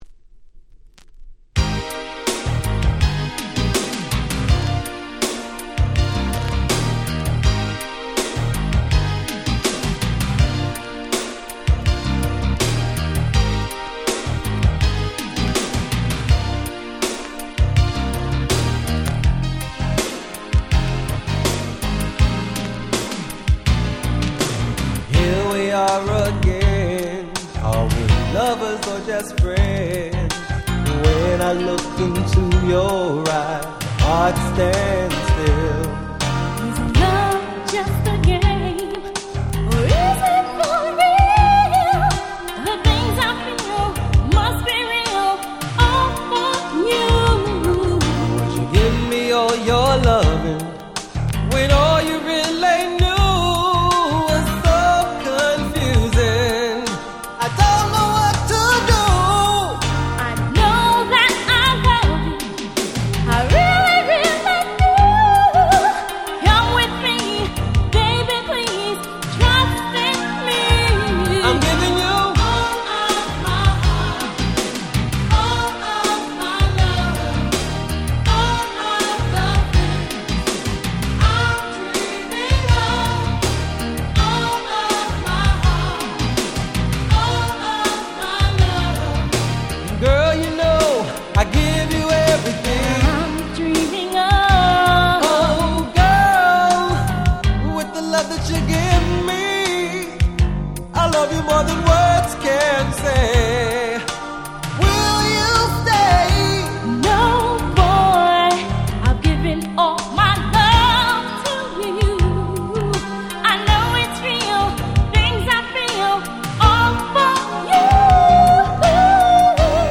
90' Very Nice R&B !!
甘くてムーディーな男女デュオ！！
90's バラード Slow Jam スロウジャム